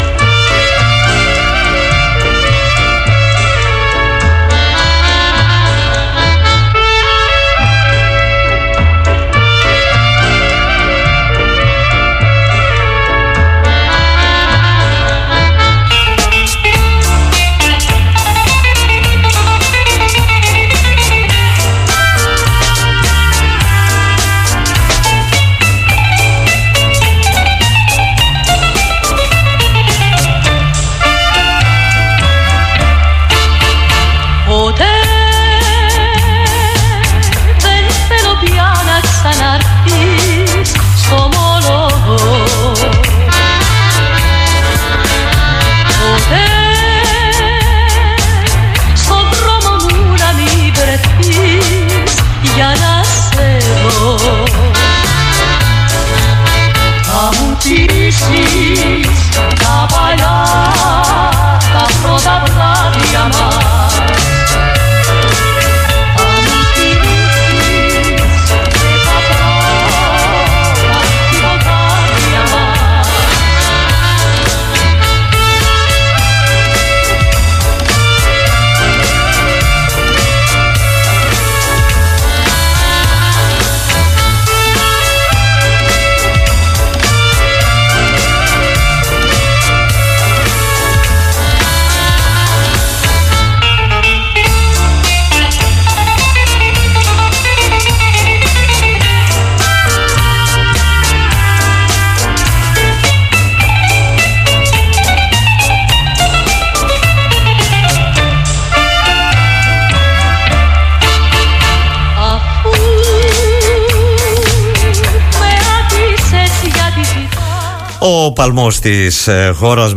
Στα όσα συνέβησαν αναφέρθηκε στην εκπομπή “Δημοσίως”
στον politica 89.8 ο δημοσιογράφος